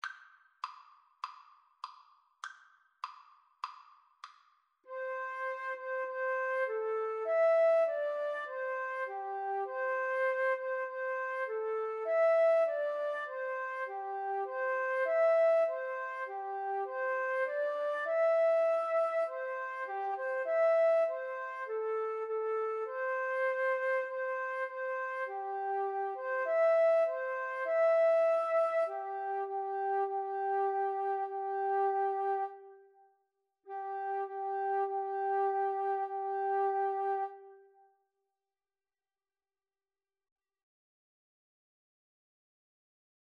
Flute 1Flute 2
Traditional Chinese
C major (Sounding Pitch) (View more C major Music for Flute Duet )
2/2 (View more 2/2 Music)
Flute Duet  (View more Easy Flute Duet Music)
Traditional (View more Traditional Flute Duet Music)
jasmine_flower_2FL_kar1.mp3